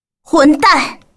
女孩骂混蛋音效免费音频素材下载